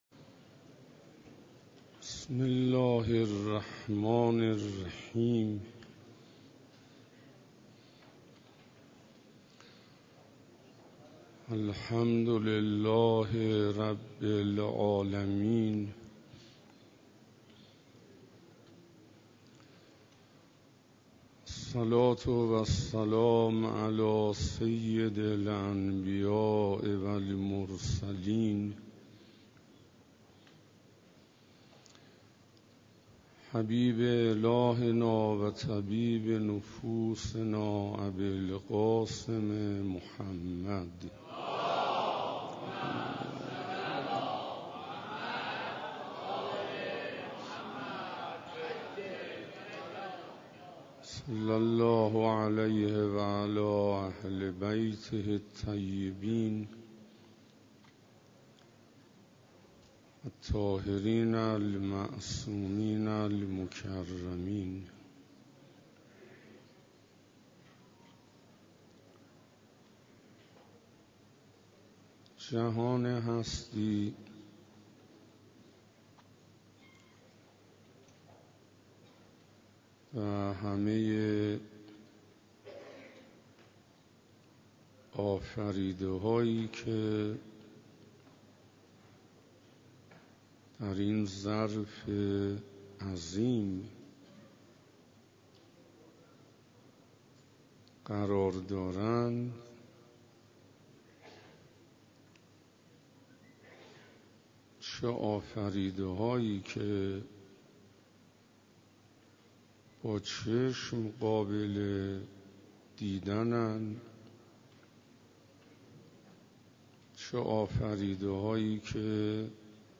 روز 2 رمضان97 - مسجد امیر علیه السلام - رمضان